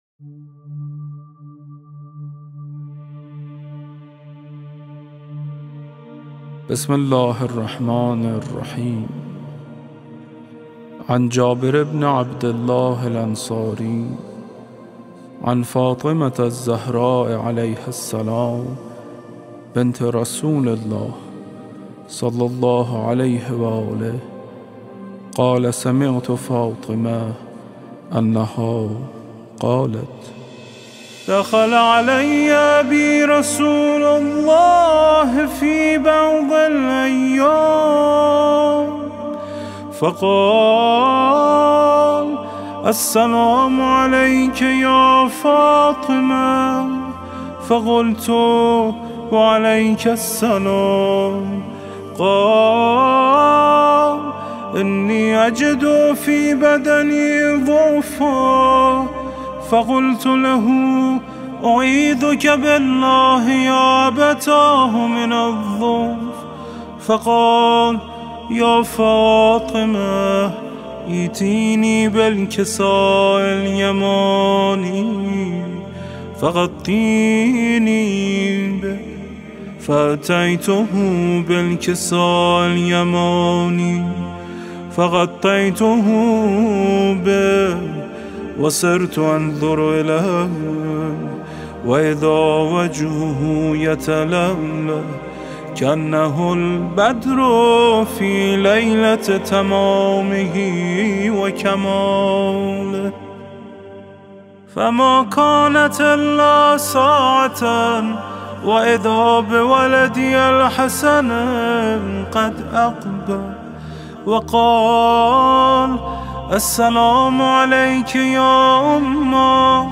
حدیث کساء با صدای علی فانی